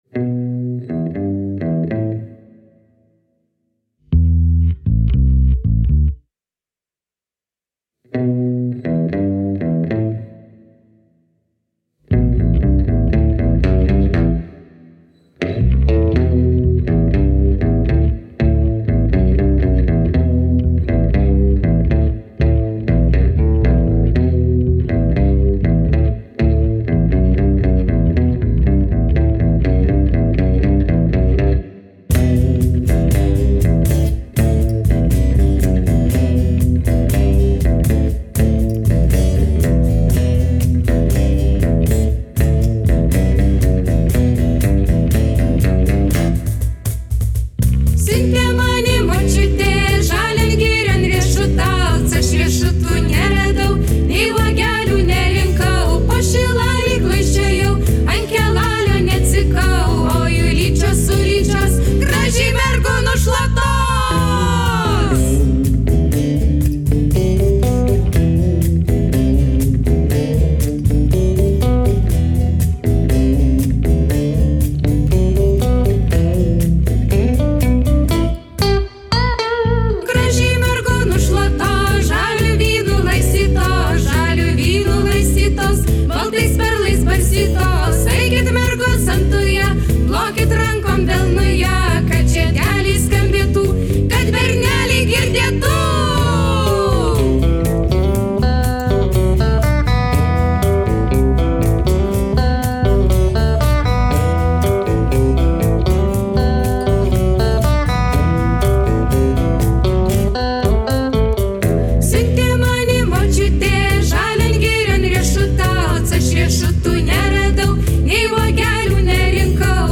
akustinė ir elektrinė gitara, pianinas, perkusija
bosinė gitara